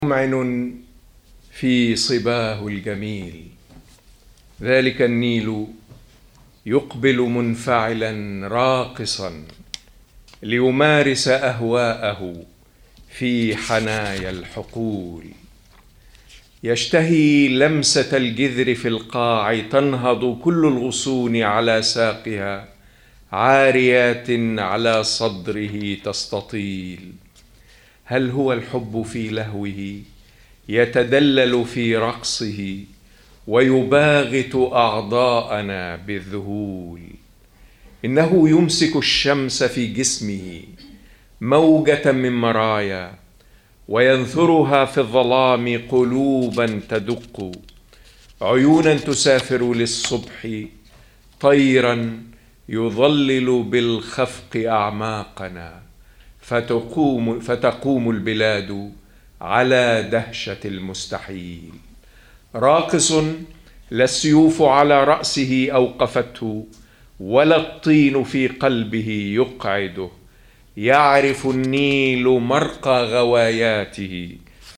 قصيدة تنشد الوصال والتواصل ألقاها محمد إبراهيم أبو سنة، الشاعر المصري مساء 28 مايو 2008 بالمكتبة العامة في مدينة جنيف.